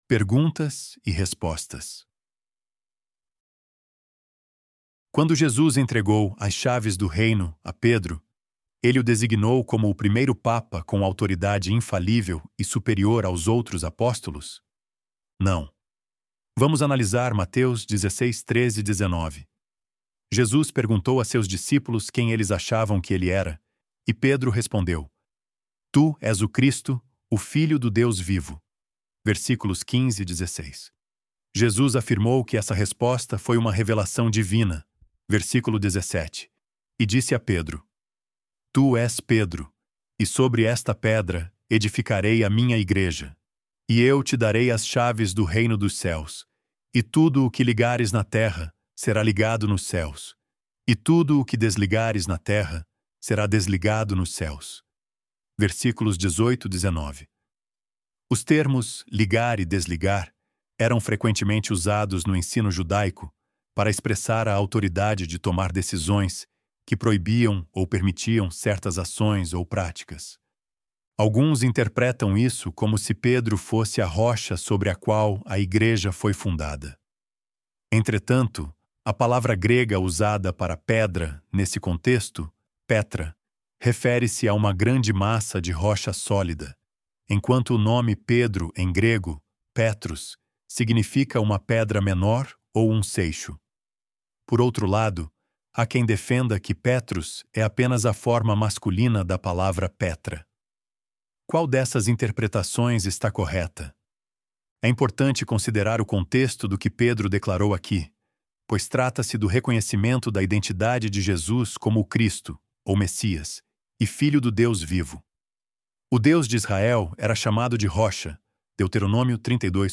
Loading the Elevenlabs Text to Speech AudioNative Player...